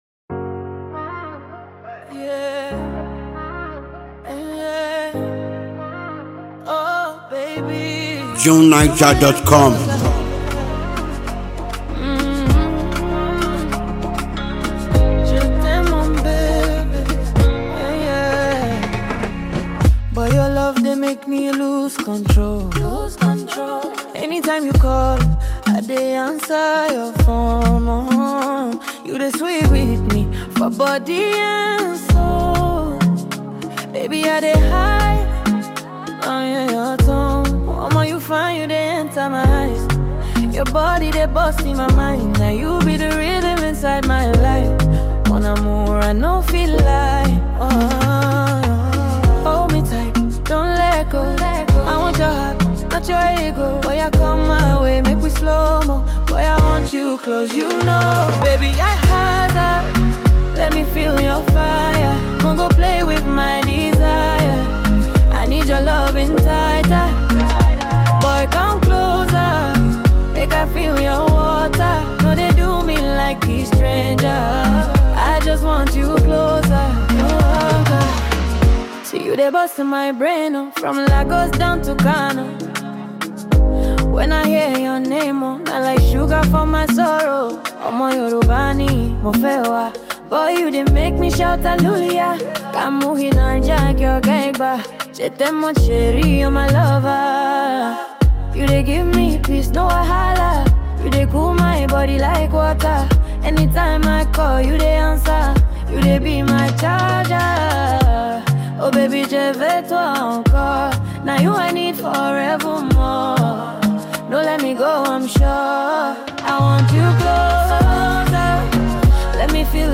Nigerian singer-songwriter
a very gifted vocalist and songwriter.